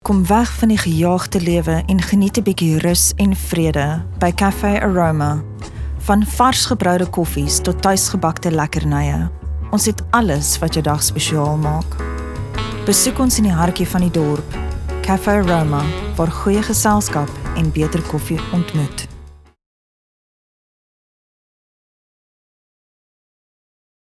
authoritative, dramatic, elegant, soothing
Elegant, Sophisticated, and sometimes, just a little bit silly.
Coffee Shop Soft Sell VO - Afrikaans